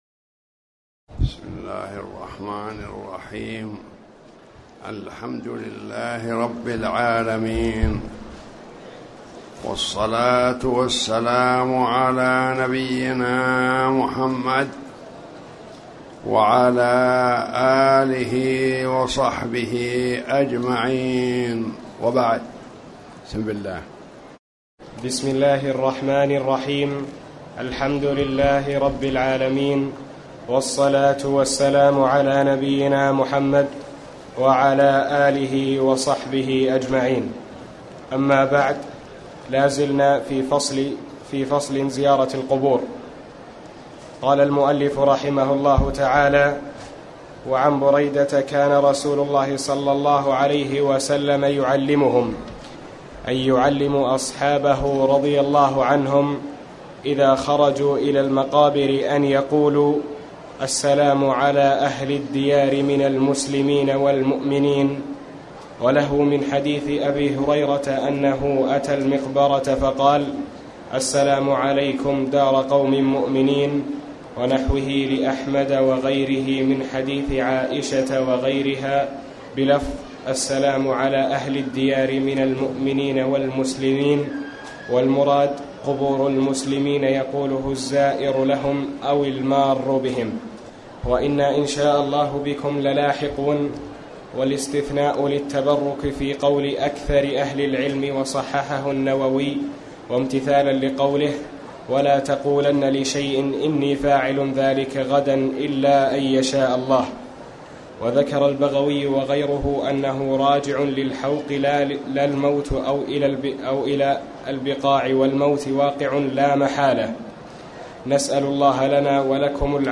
تاريخ النشر ٢٨ رجب ١٤٣٩ هـ المكان: المسجد الحرام الشيخ